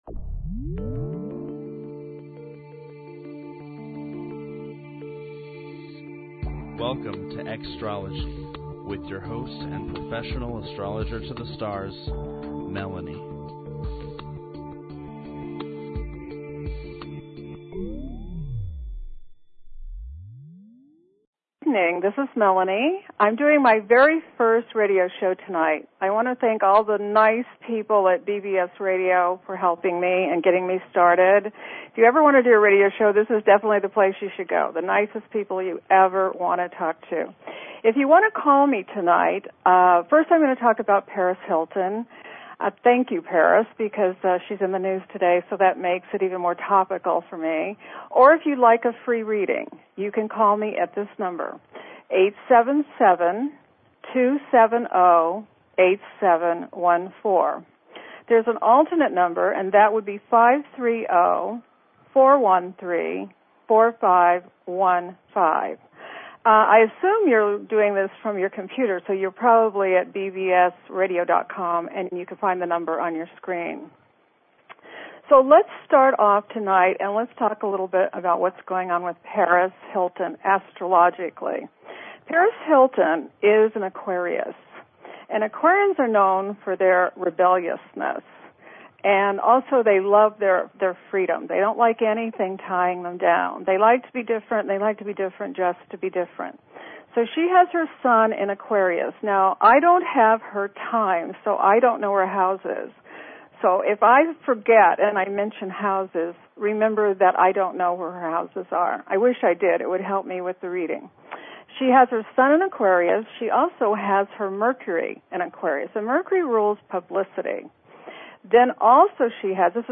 Talk Show Episode, Audio Podcast, Xtrology cont and Courtesy of BBS Radio on , show guests , about , categorized as